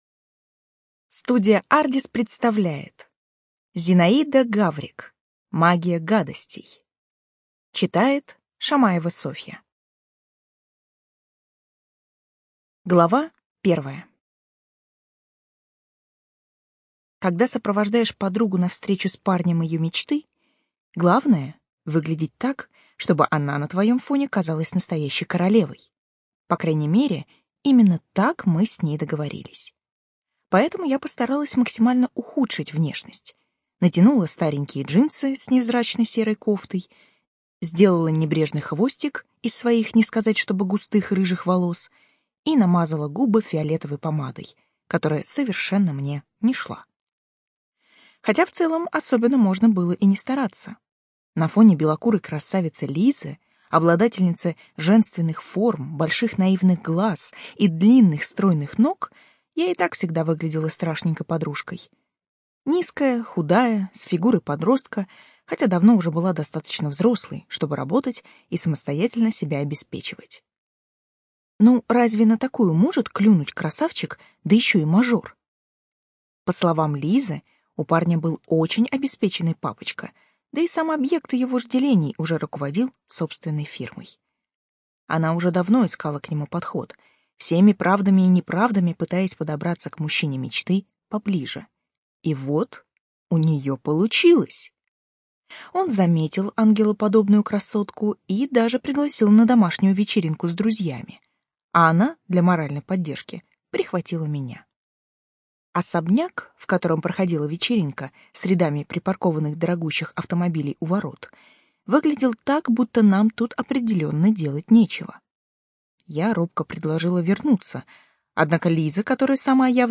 ЖанрФэнтези
Студия звукозаписиАрдис